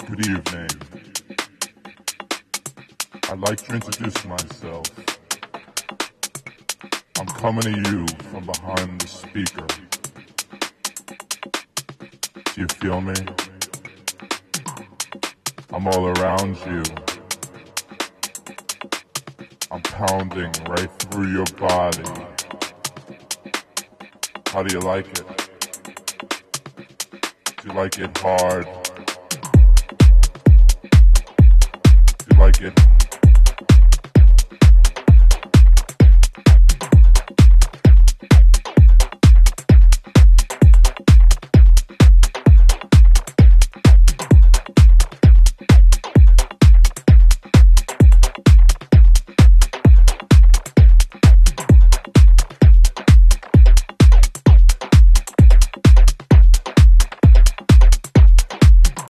It is a pulsating, hard hitting tune.